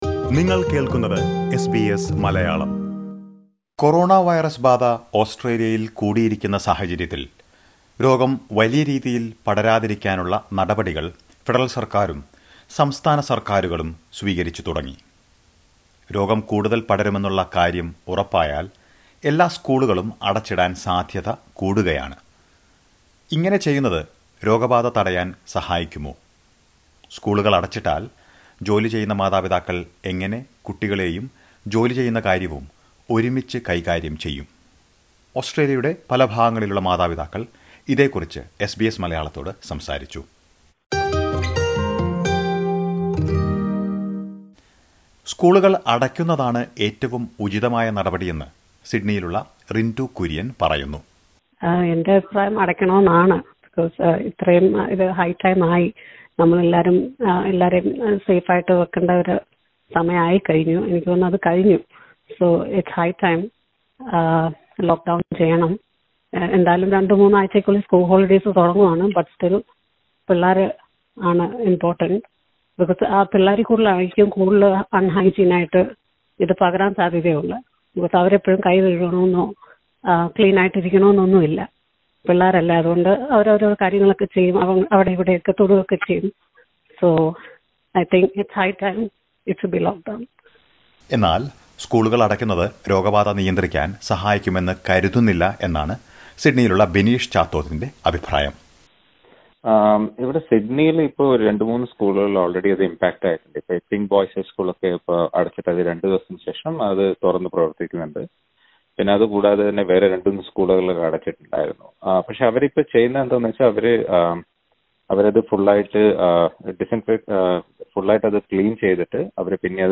സ്കൂളുകൾ അടച്ചാൽ കുട്ടികളെ നോക്കുന്ന കാര്യവും ജോലിയും മാതാപിതാക്കൾ എങ്ങനെ ഒരുമിച്ചു കൊണ്ടുപോകും? ഓസ്‌ട്രേലിയയുടെ വിവിധ ഭാഗങ്ങളിലുള്ള മലയാളി രക്ഷിതാക്കൾ ഇതേക്കുറിച്ച് എന്ത് ചിന്തിക്കുന്നു എന്ന് കേൾക്കാം പ്ലെയറിൽ നിന്ന്.